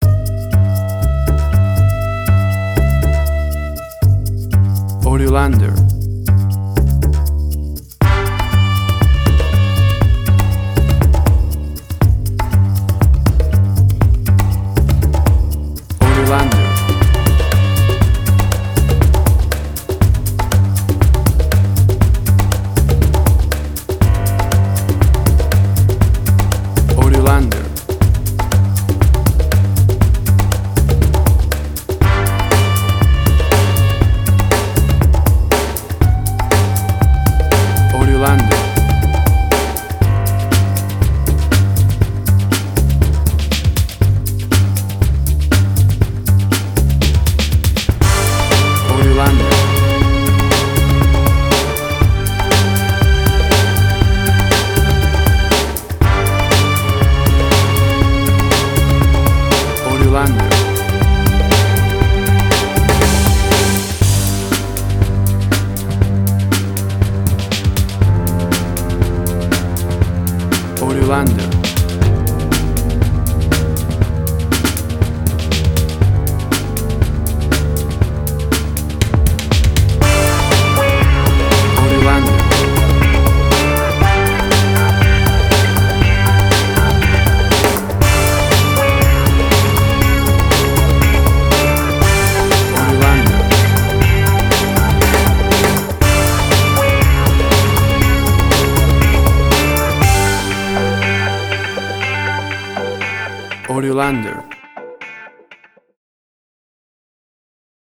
Suspense, Drama, Quirky, Emotional.
chase, action, intense, 70’s funky sounds.
WAV Sample Rate: 16-Bit stereo, 44.1 kHz
Tempo (BPM): 120